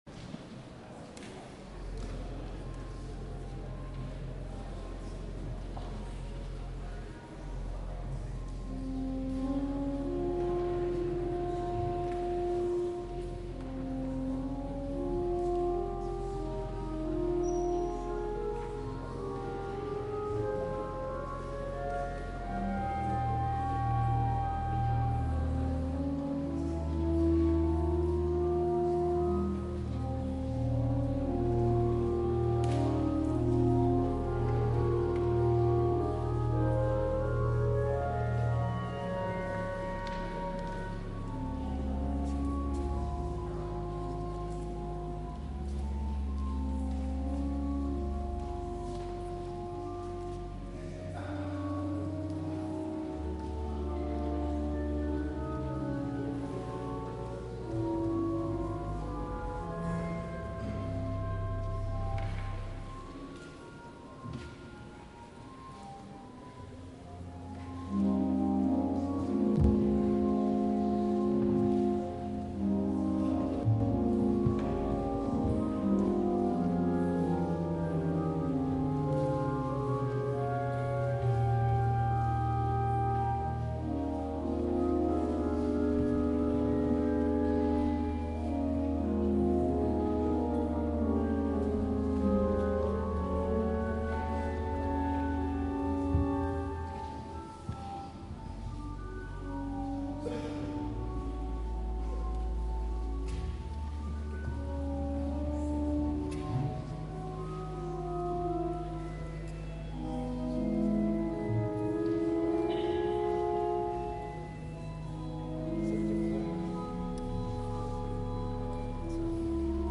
2013 Complete Service Recordings from St. John's Cathedral, Jacksonville Florida